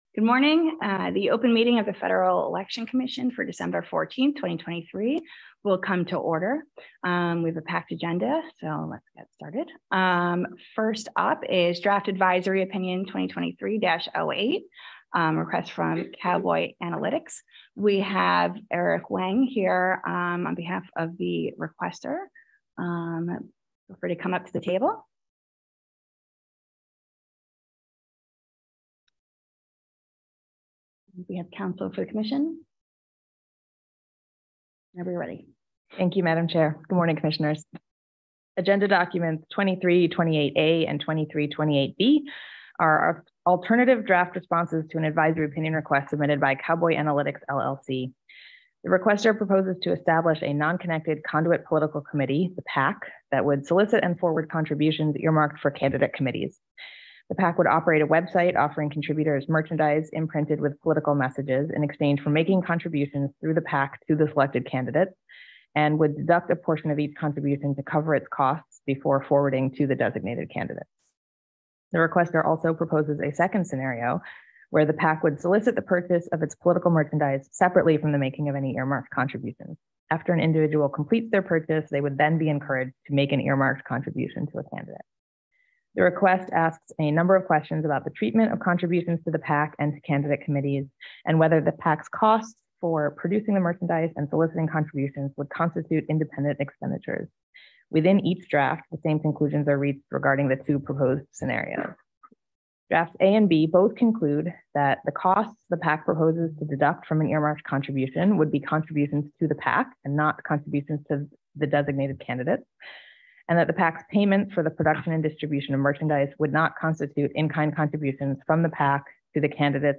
December 14, 2023 open meeting of the Federal Election Commission
The Commission considers new regulations, advisory opinions and other public matters at open meetings, which are typically held on Thursdays at 10:00 a.m. at FEC headquarters, 1050 First Street NE, Washington, DC.